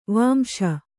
♪ vāmśa